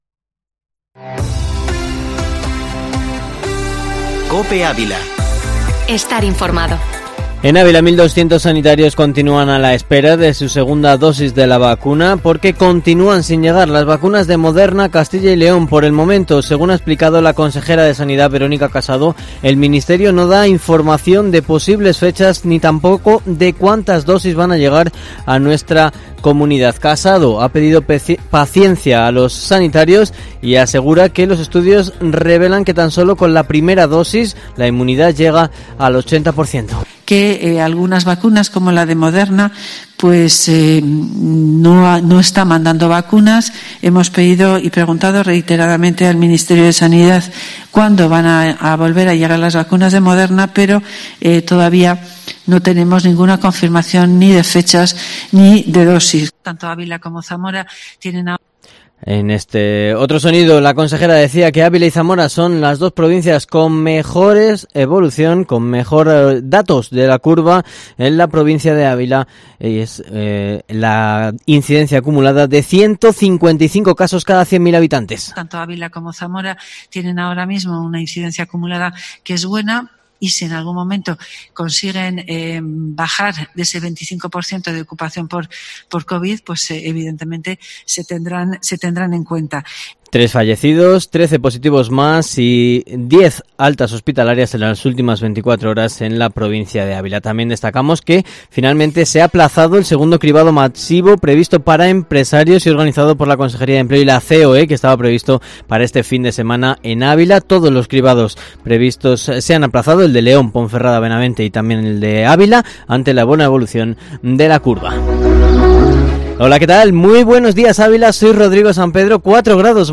Informativo matinal Herrera en COPE Ávila 24/02/2021